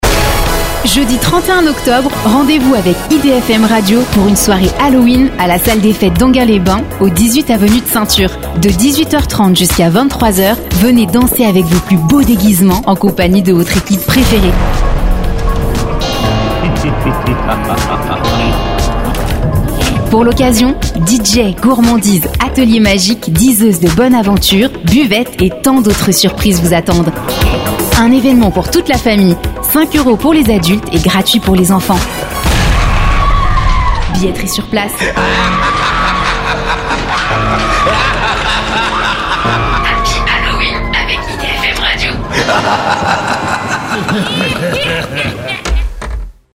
Sua entrega segura e acolhedora se adapta a comerciais, narrações e rádio, tornando-a uma escolha versátil para marcas que buscam serviços profissionais de locução.
Trailers de filmes
Microfone: Neumann TLM 103